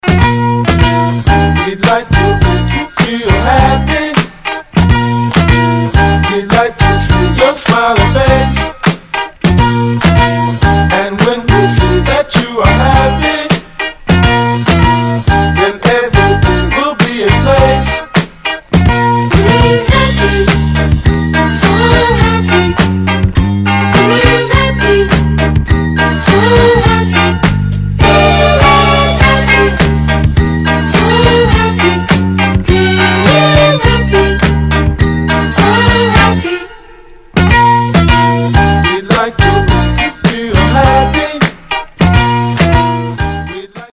Mid Number